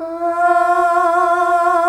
AAAAH   F.wav